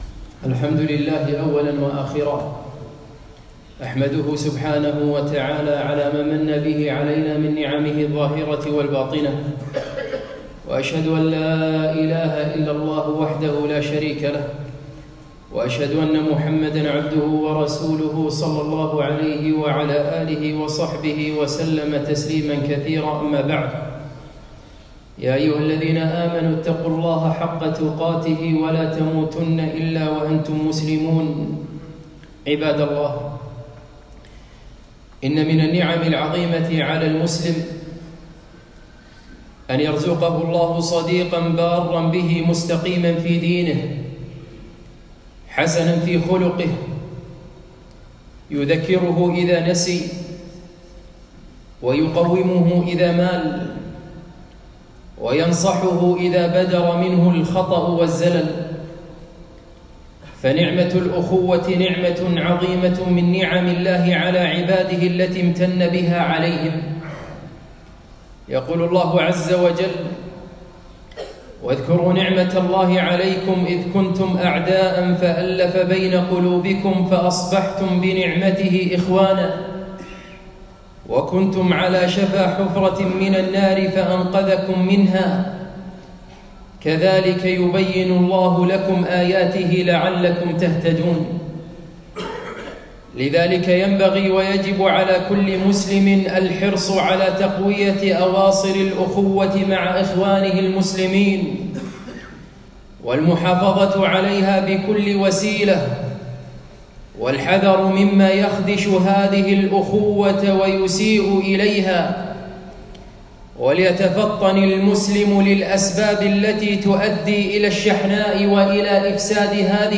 خطبة - فأصبحتم بنعمته إخوانا